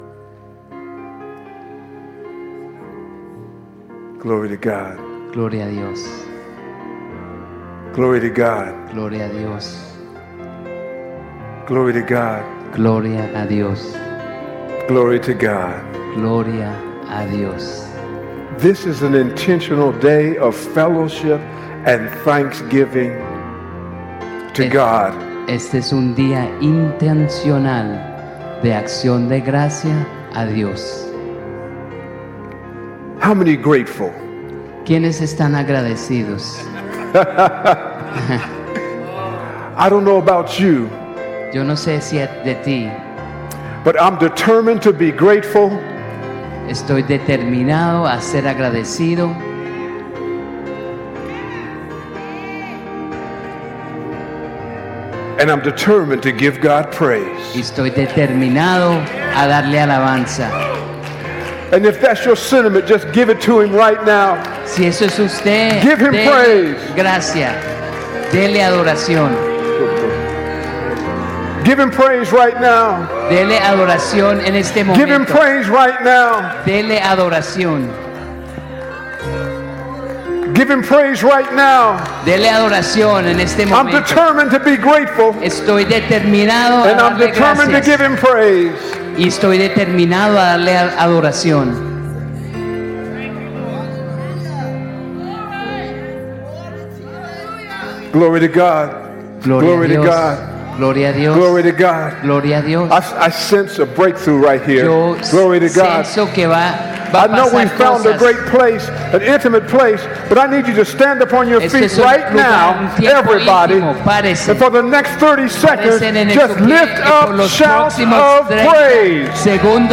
Teachings